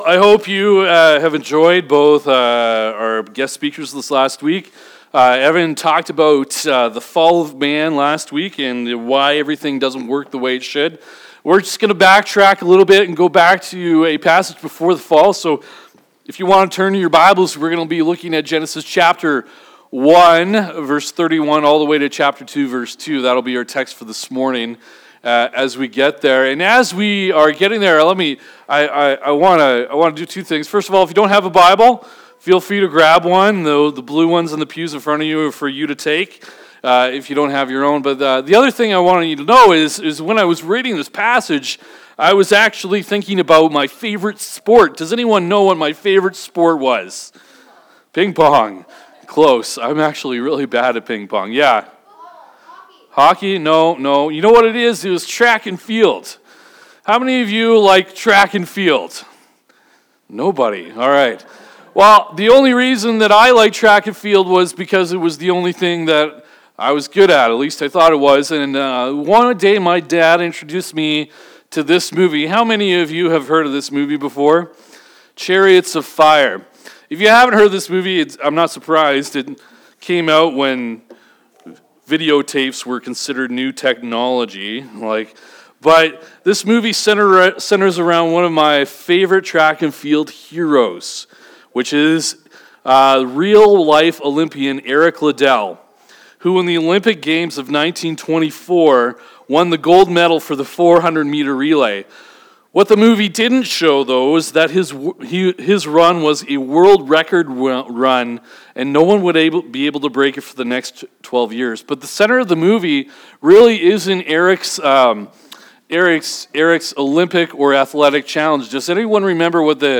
2021 Current Sermon Rest & Remember Start.